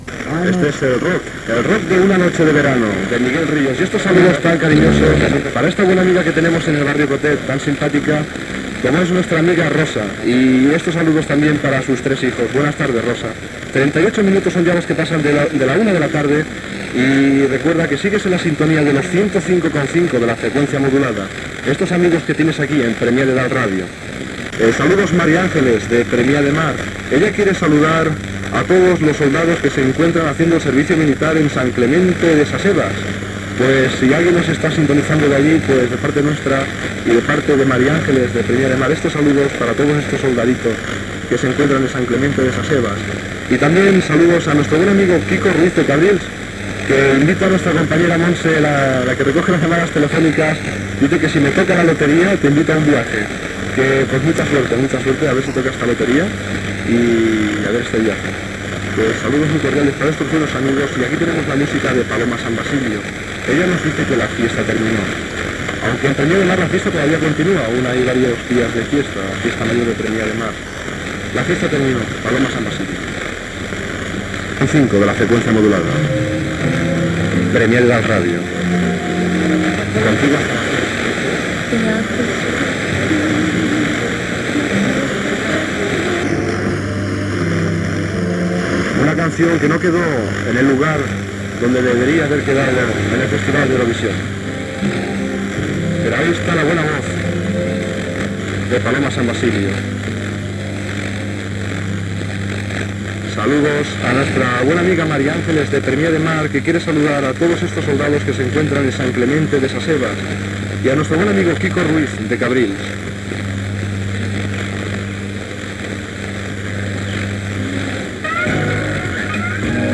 Salutacions de les oïdores i temes musicals i identificacions de l'emissora.
Musical
FM